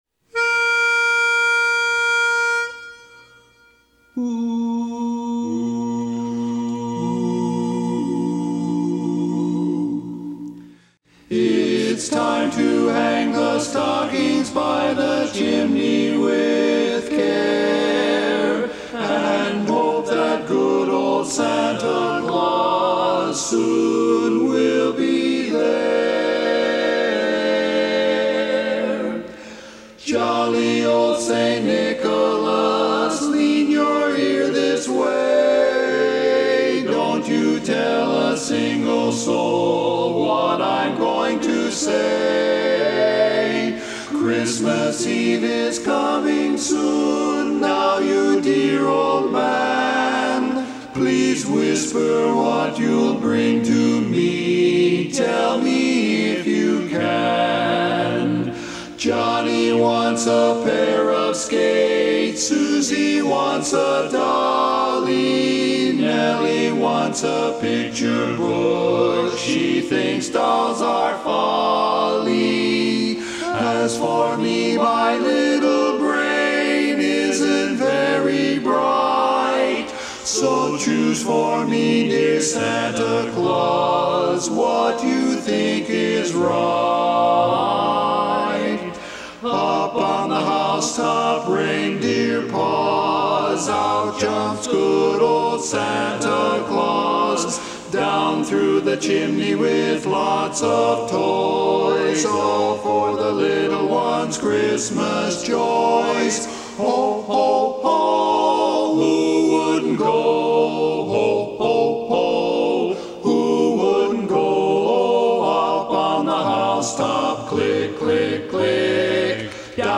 Barbershop